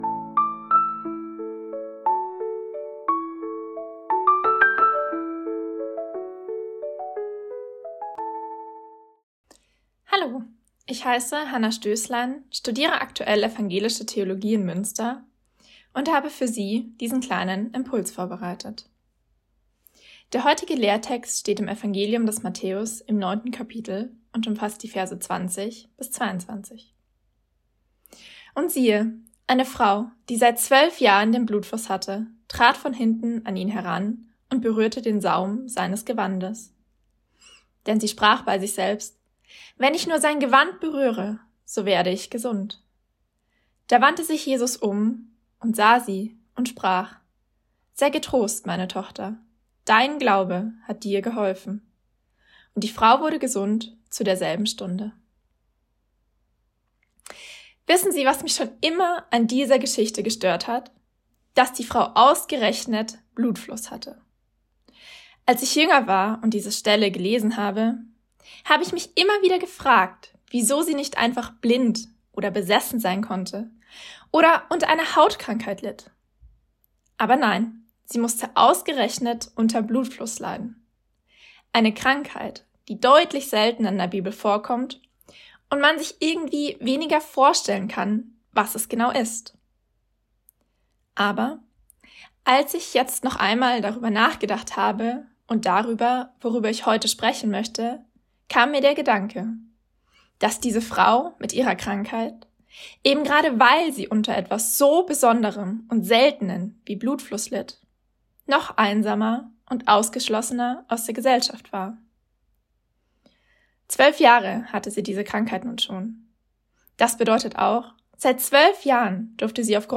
Losungsandacht für Montag, 12.05.2025